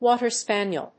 アクセントwáter spàniel